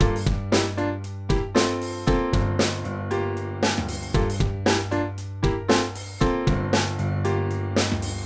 vintage-drive-para
vintage-drive-para.mp3